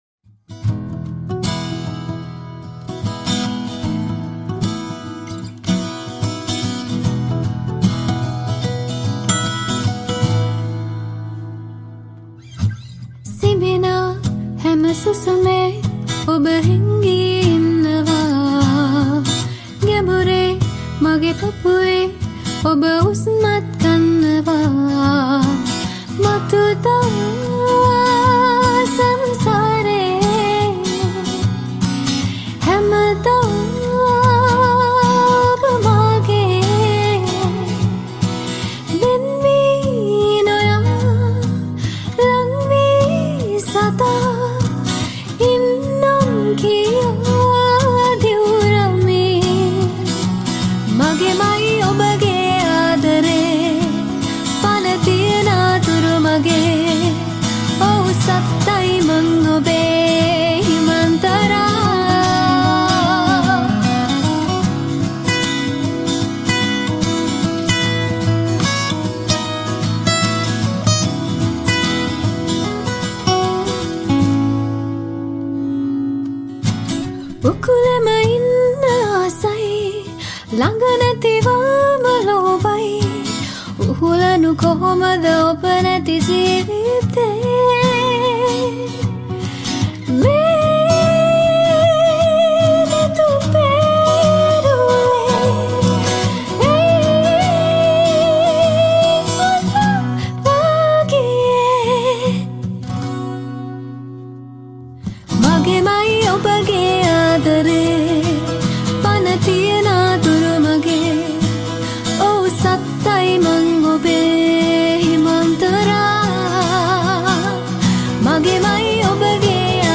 Vocals
Guitars